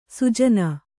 ♪ sujana